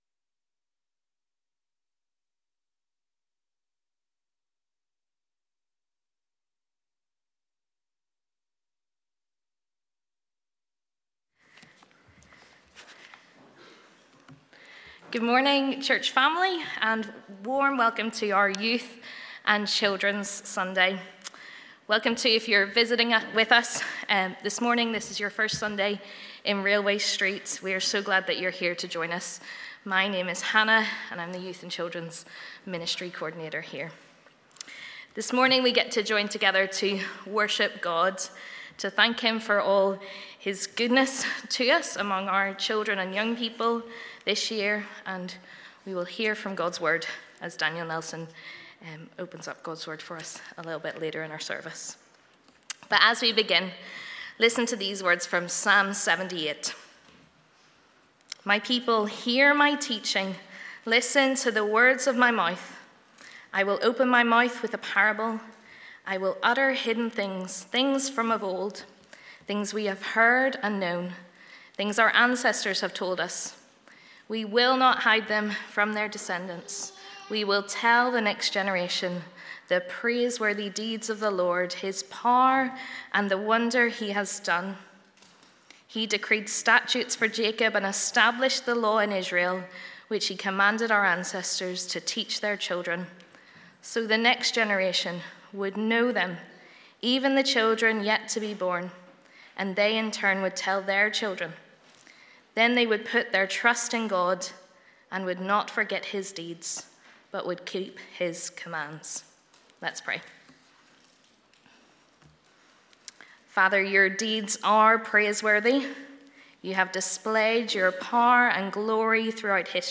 This morning we celebrate God's goodness towards us in our youth and children's ministries and thank God for the children in our congregation.